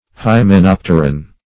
hymenopteran - definition of hymenopteran - synonyms, pronunciation, spelling from Free Dictionary
Hymenopteran \Hy`me*nop"ter*an\, n. (Zool.)